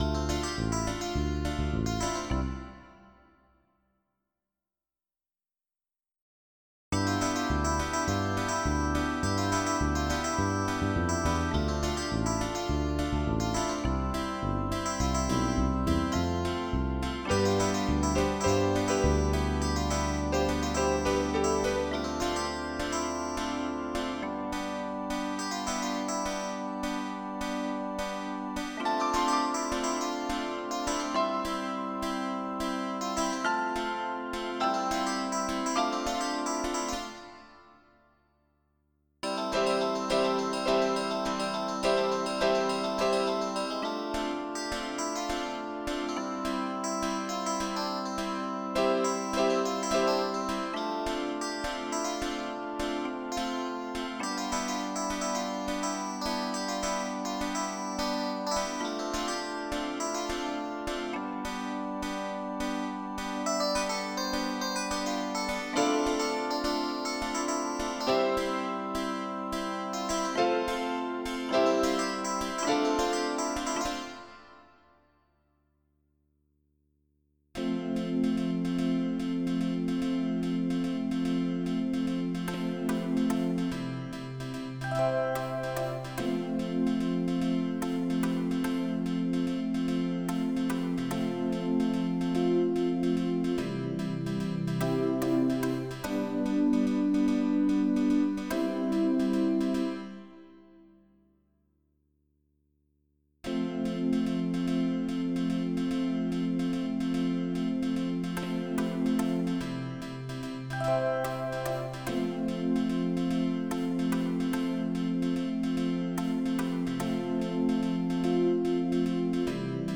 Oldies
General MIDI